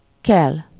Ääntäminen
Ääntäminen Tuntematon aksentti: IPA: /ˈkɛl/ Haettu sana löytyi näillä lähdekielillä: unkari Käännös 1. chou frisé {m} 2. chou de Savoie Luokat Latinasta johdetut sanat Substantiivit Verbit Vihannekset